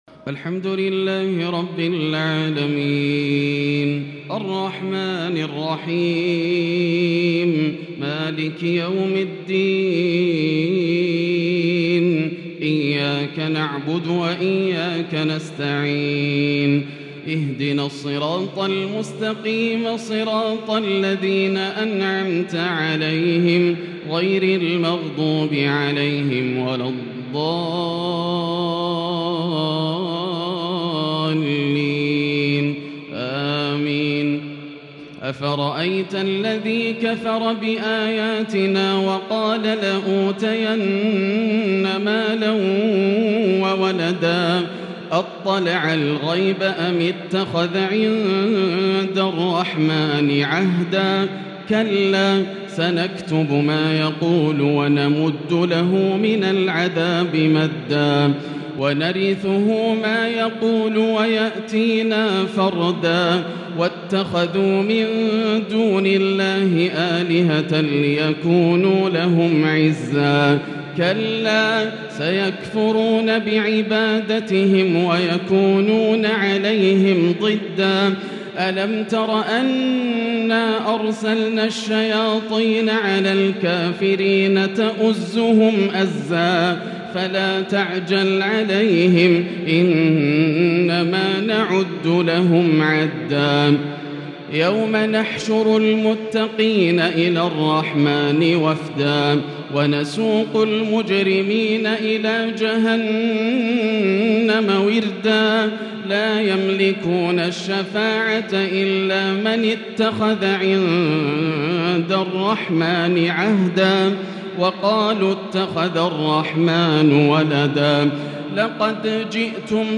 تراويح ليلة 21 رمضان 1442هـ من سورة مريم (77)إلى طه (82) Taraweeh 21 th night Ramadan 1442H > تراويح الحرم المكي عام 1442 🕋 > التراويح - تلاوات الحرمين